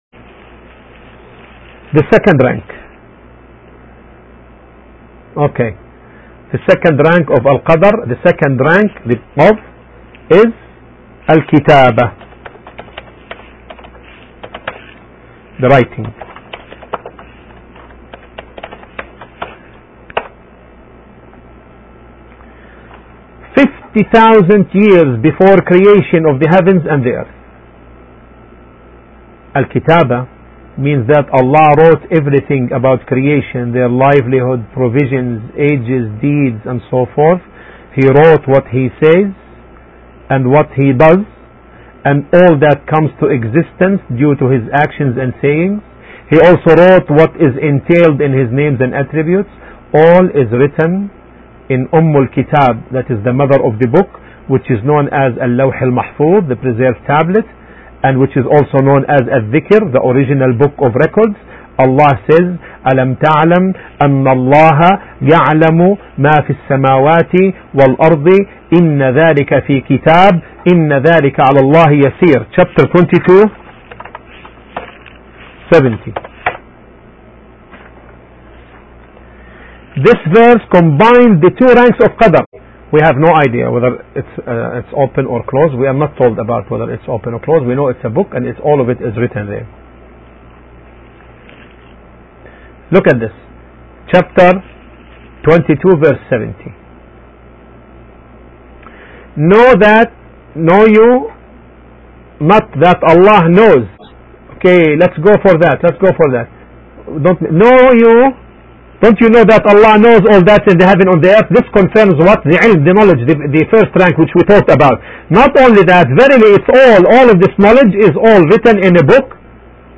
We should understand this pillar based on the Quranic verses and the authentic Sunnah not based on personal opinions. Pre-decree of Allah has four ranks: knowledge, writing, willing and creation. The lecturer answers an important question: